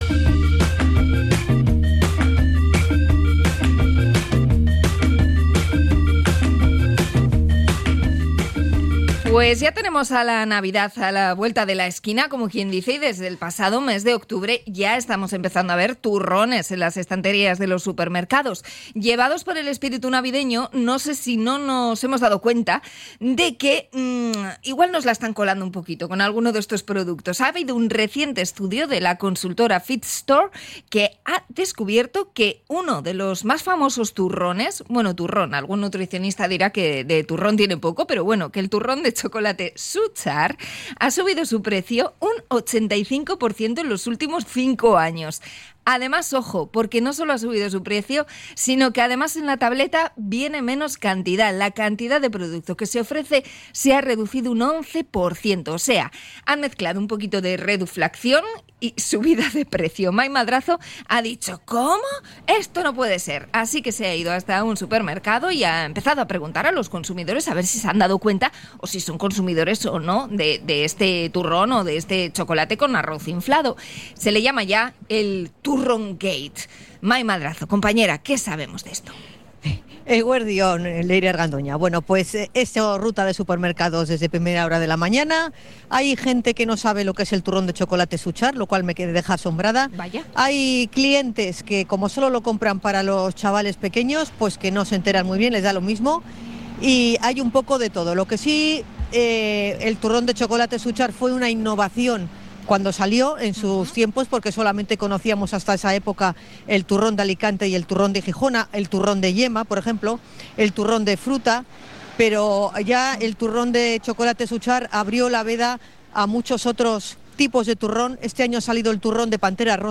Hablamos con los consumidores a la puerta del supermercado